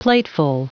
Prononciation du mot plateful en anglais (fichier audio)
Prononciation du mot : plateful